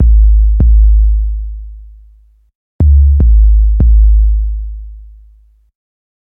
Trill Bass Kick Loop 2
描述：轰隆隆，咚咚咚，808's
Tag: 150 bpm Hip Hop Loops Drum Loops 1.08 MB wav Key : Unknown